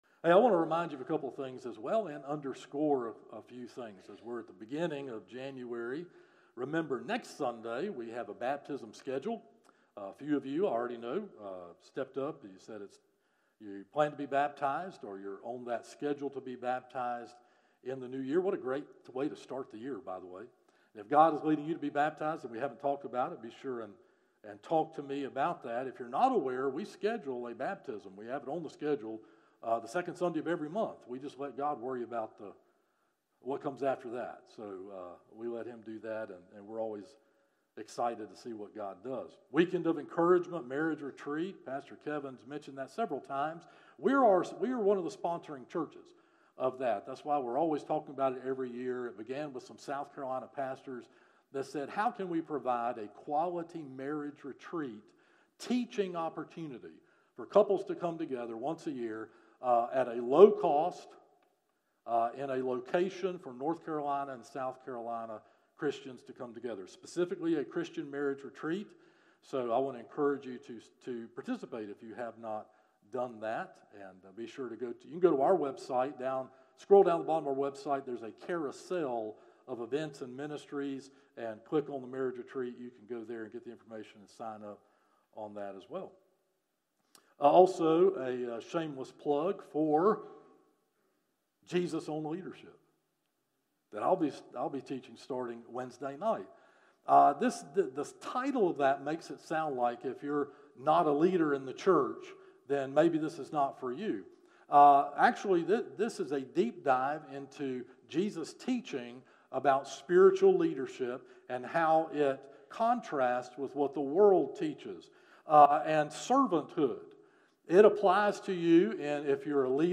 Sermons - First Baptist Church of Shallotte
From Series: "Morning Worship - 11am"